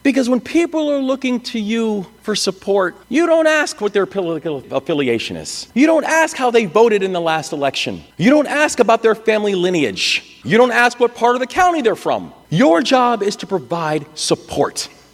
During his speech to the Maryland Association of Counties, Governor Wes Moore said that the steady leadership in the state is what will get Maryland through the issues it is facing internally and externally.